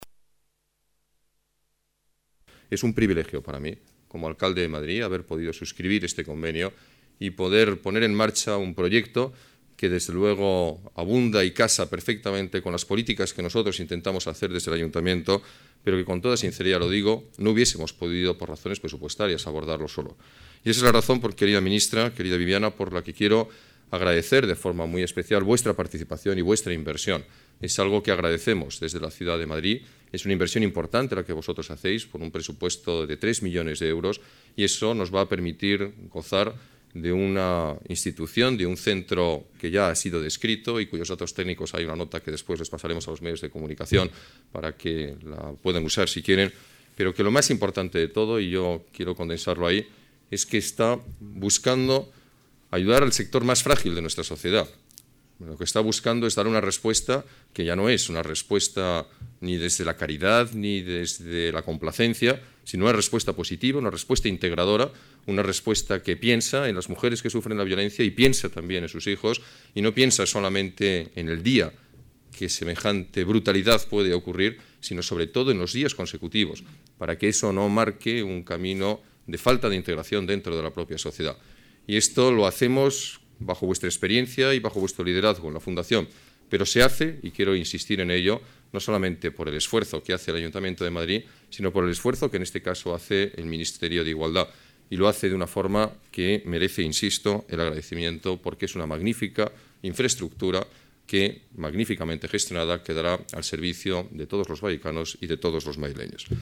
Nueva ventana:Declaraciones del alcalde de Madrid, Alberto Ruiz-Gallardón: centro de atención integral mujeres víctimas de la violencia